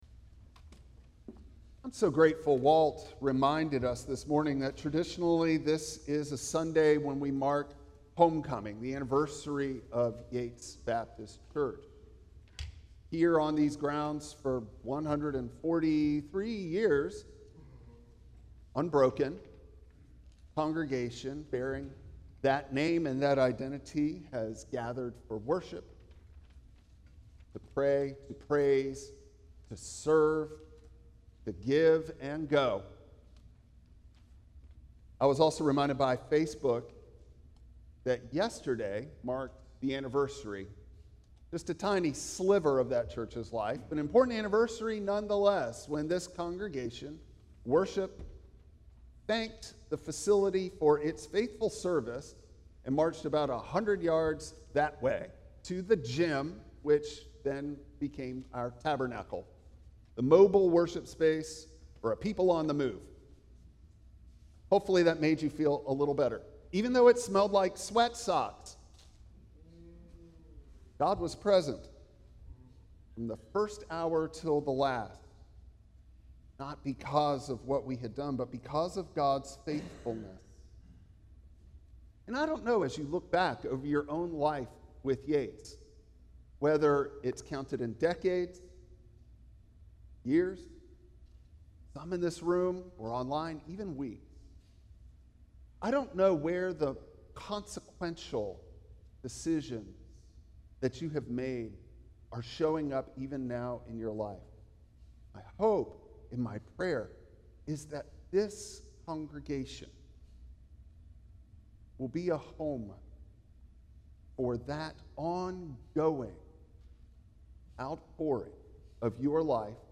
Exodus 14:8-14 Service Type: Traditional Service Bible Text